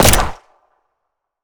gun_pistol_shot_05.wav